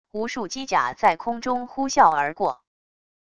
无数机甲在空中呼啸而过wav音频